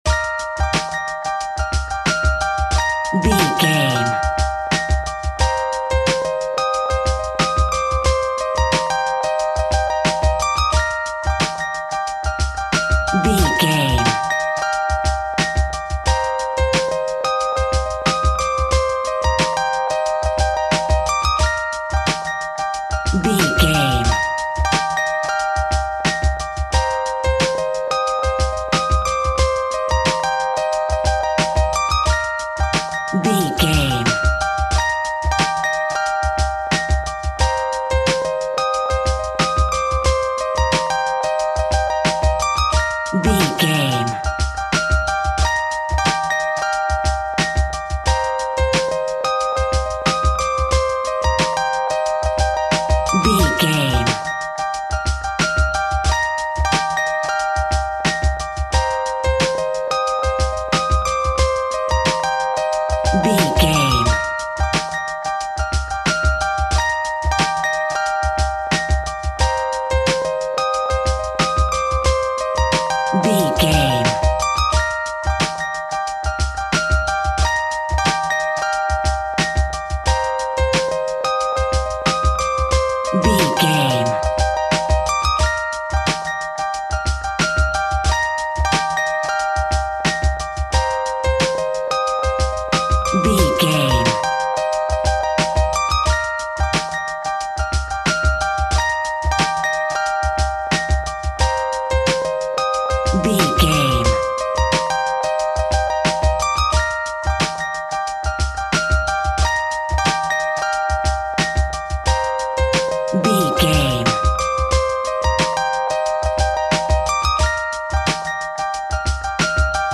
Ionian/Major
E♭
synth drums
synth bass